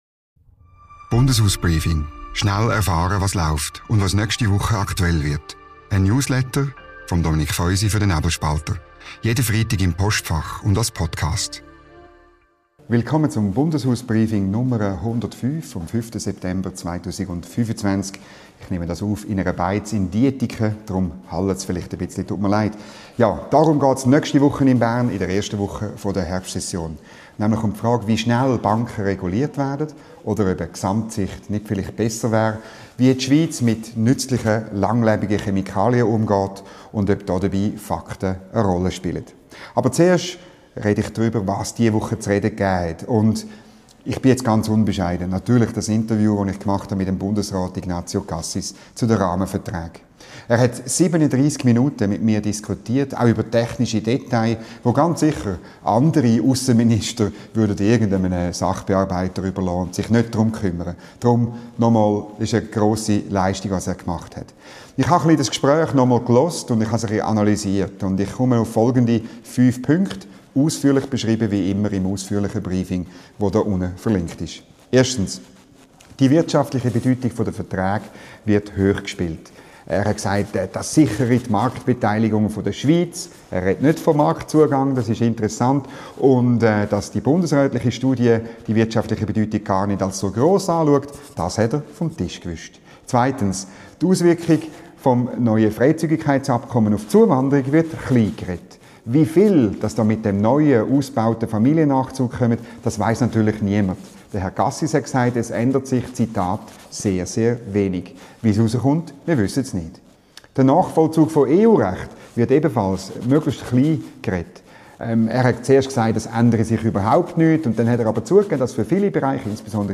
Ignazio Cassis im Interview zu den Rahmenverträgen.